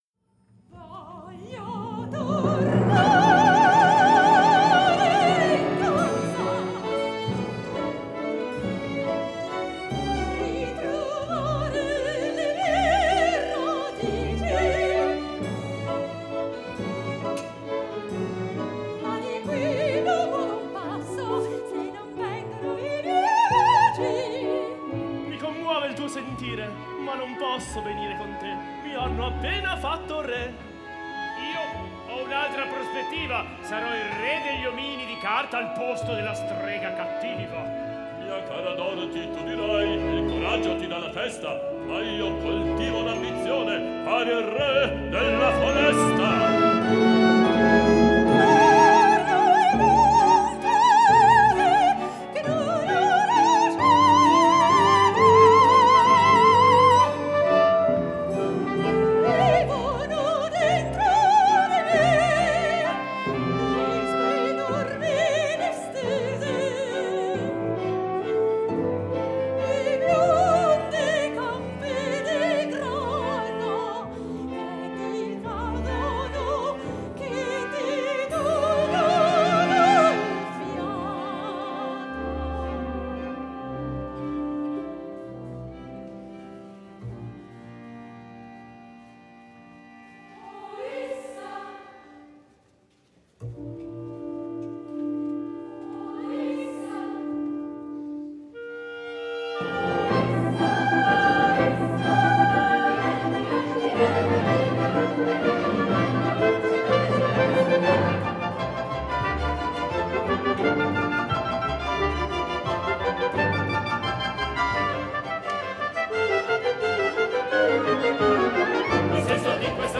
Registrazioni della prima rappresentazione italiana del Mago di Oz